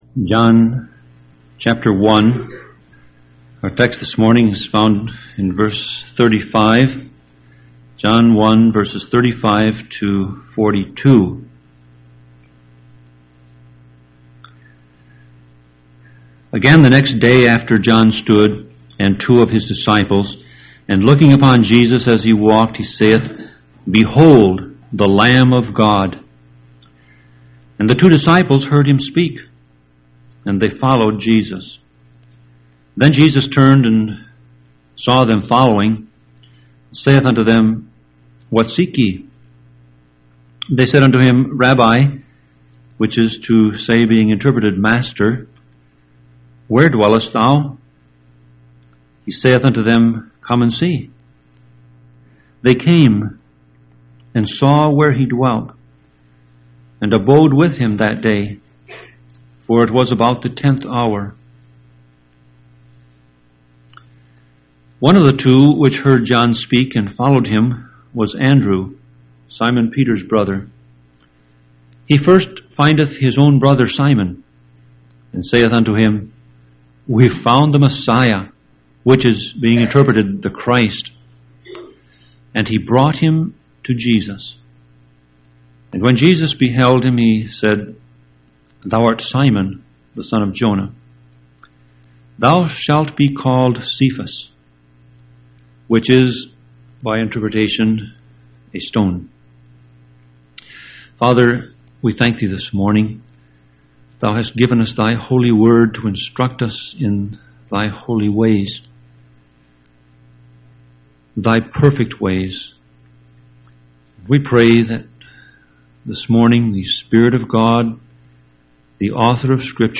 Sermon Audio Passage: John 1:35-42 Service Type